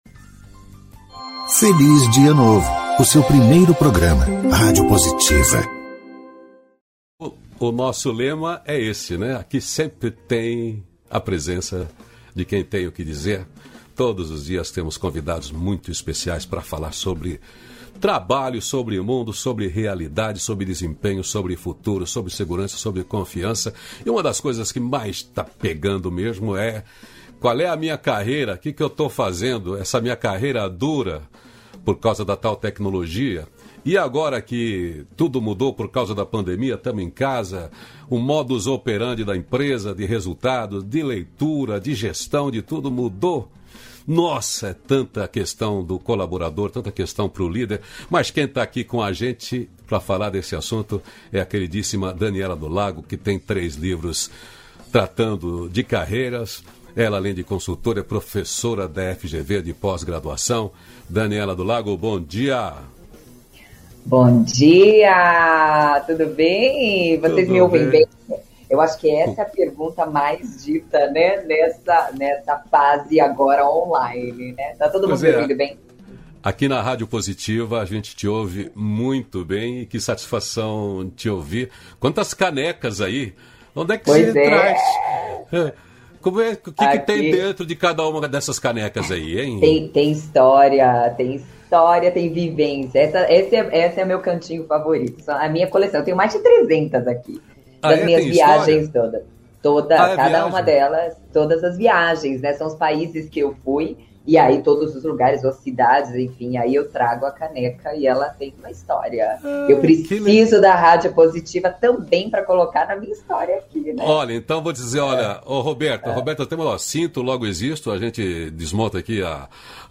entrevista
238-feliz-dia-novo-entrevista.mp3